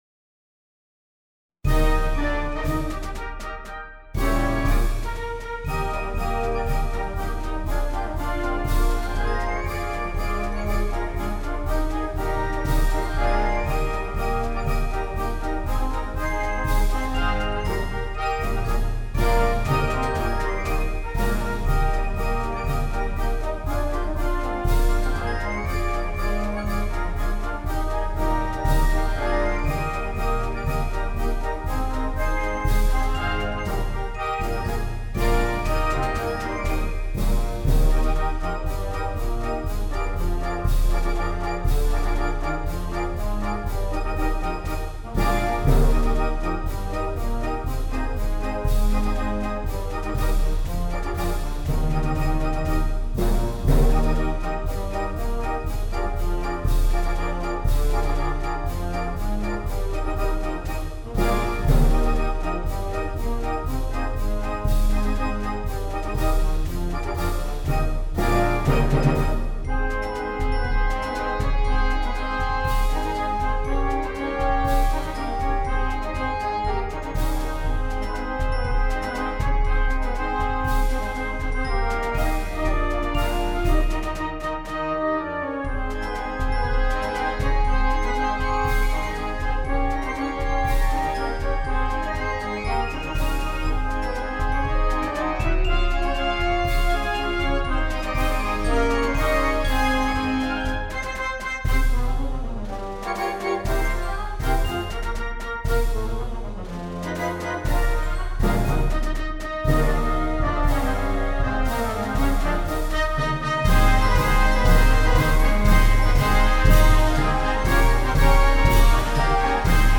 Genre: Band
Instrumentation
Piccolo
Euphonium
Timpani [3 drums]
Percussion 1 [2 players]: Snare Drum, Bass Drum
Percussion 2: Cymbals